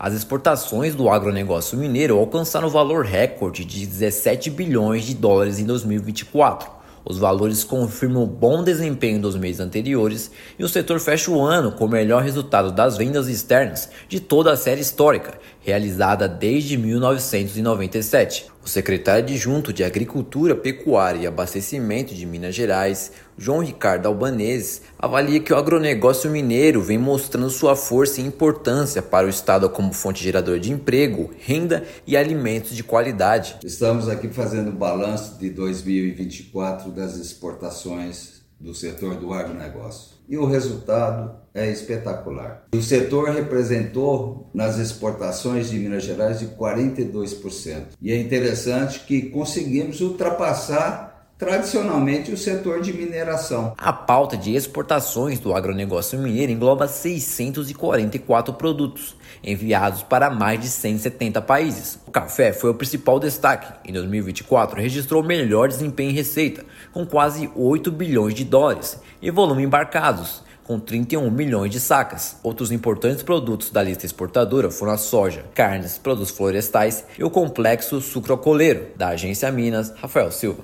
No acumulado do ano, segmento superou em 2,5% a receita da mineração, até então tradicionalmente dominante; bom desempenho é puxado pela valorização e aumento da demanda do café. Ouça matéria de rádio.